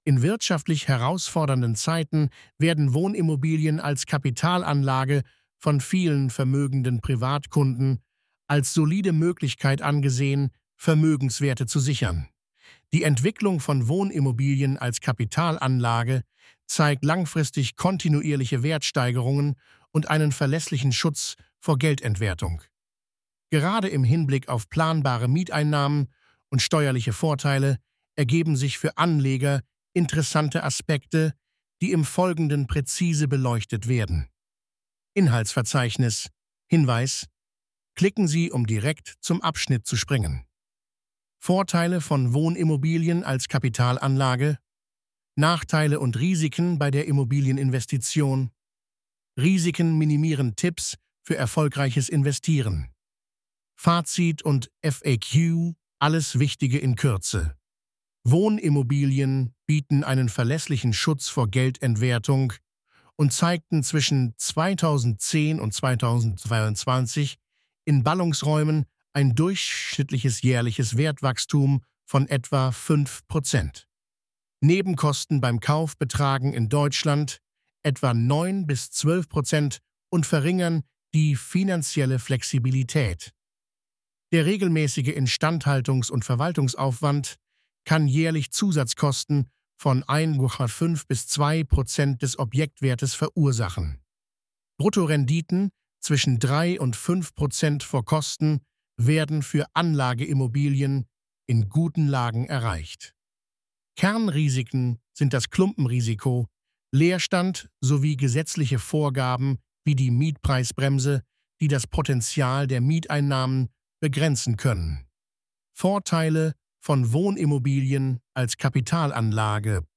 Lassen Sie sich den Artikel von mir vorlesen.